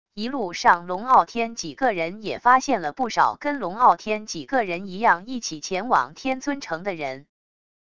一路上龙傲天几个人也发现了不少跟龙傲天几个人一样一起前往天尊城的人wav音频生成系统WAV Audio Player